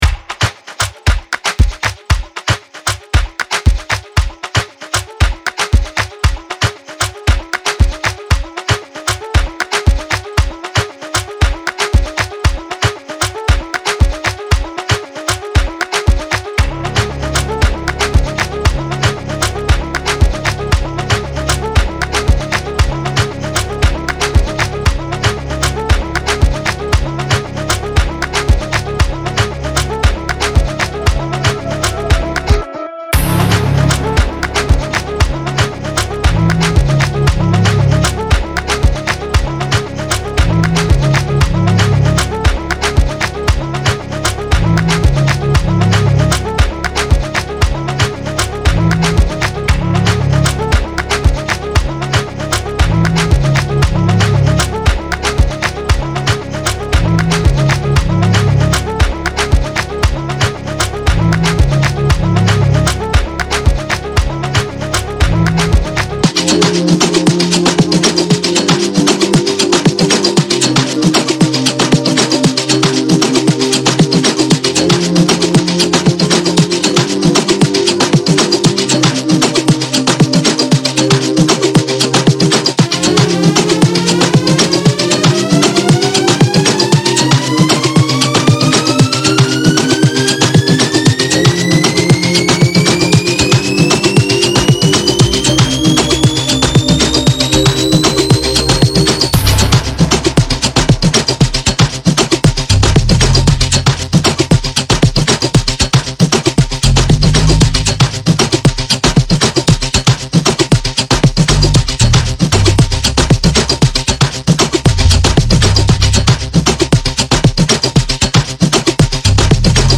2026-01-11 Afro House · Tribal House 83 推广